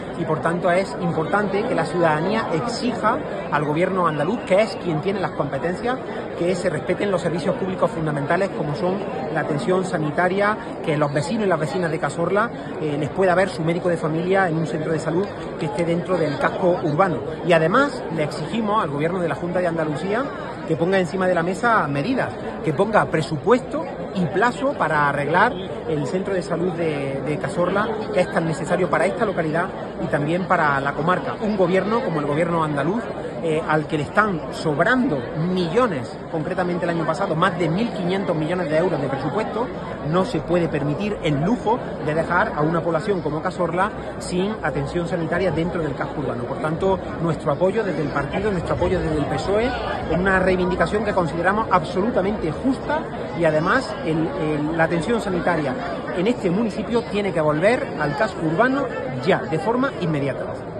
El secretario general del PSOE de Jaén, Juan Latorre, calificó hoy de “barbaridad” la decisión de la Junta de Andalucía de trasladar el centro de salud de Cazorla fuera del casco urbano. Latorre hizo estas declaraciones desde este municipio, donde acudió a apoyar las reivindicaciones de sus vecinos y vecinas en una multitudinaria manifestación en la que también ha estado presente el alcalde, José Luis Olivares, además de otros responsables socialistas locales, comarcales y provinciales.